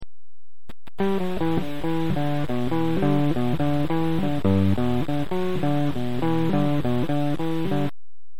ポイント：この曲はいかになめらかに、まさにランニングベースという感じに弾くかが勝負の決め手となる。